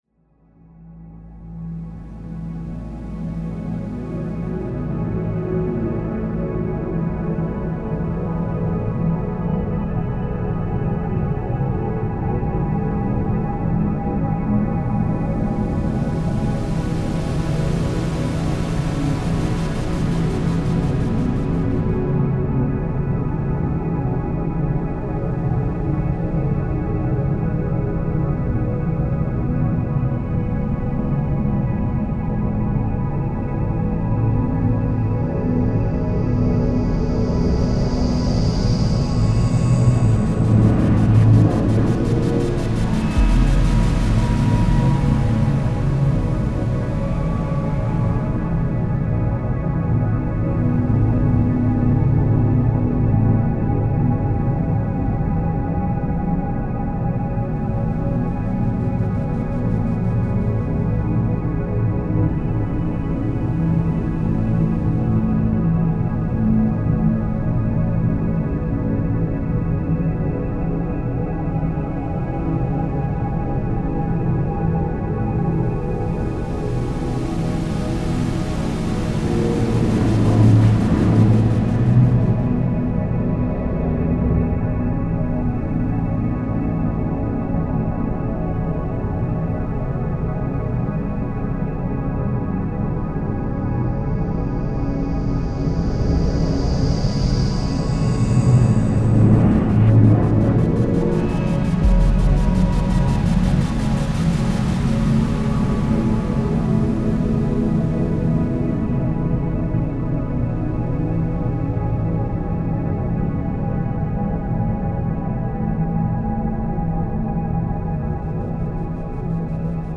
DeepSpaceAmbient.mp3